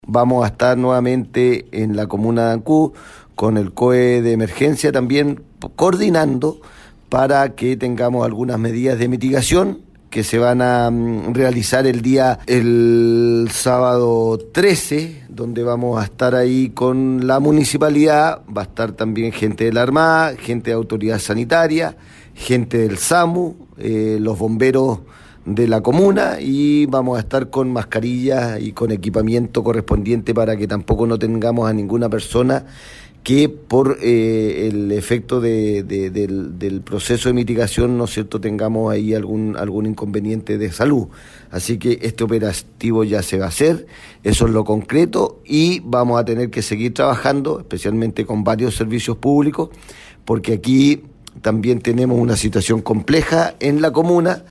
Fernando Bórquez, Gobernador de Chiloé, señaló que se reunirán varios servicios públicos con todos los elementos de seguridad necesarios para desarrollar esta limpieza.